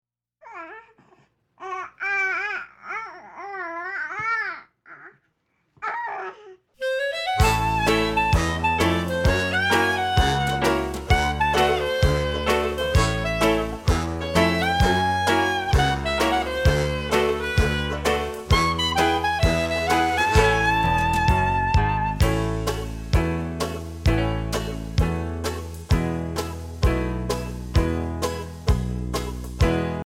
Listen to a sample of the instrumenal track.